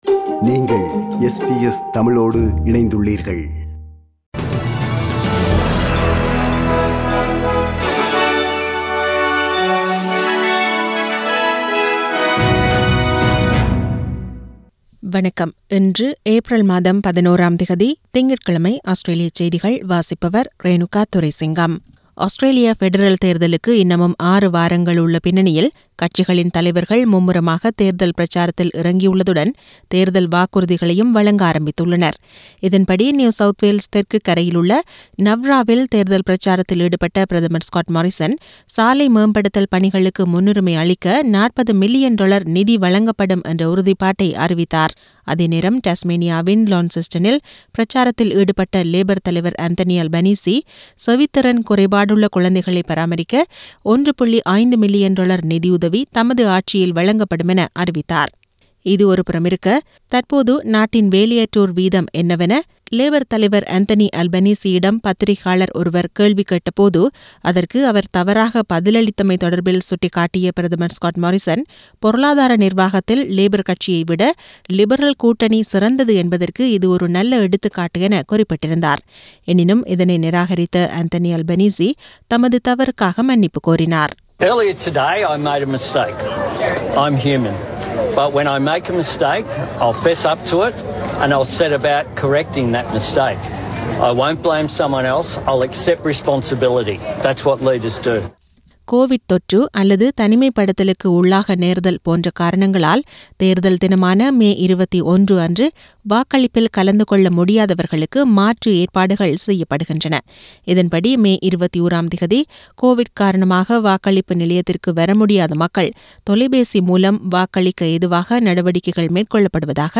Australian news bulletin for Monday 11 Apr 2022.